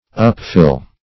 upfill - definition of upfill - synonyms, pronunciation, spelling from Free Dictionary Search Result for " upfill" : The Collaborative International Dictionary of English v.0.48: Upfill \Up*fill"\, v. t. To fill up.